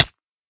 hit1.ogg